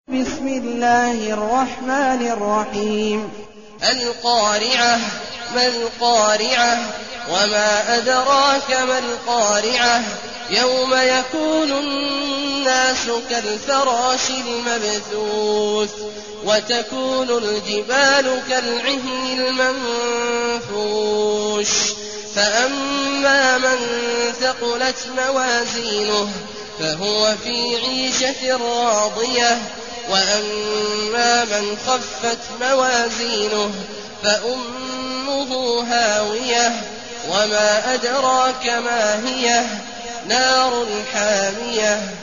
المكان: المسجد النبوي الشيخ: فضيلة الشيخ عبدالله الجهني فضيلة الشيخ عبدالله الجهني القارعة